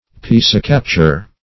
Search Result for " piscicapture" : The Collaborative International Dictionary of English v.0.48: Piscicapture \Pis"ci*cap`ture\, n. Capture of fishes, as by angling.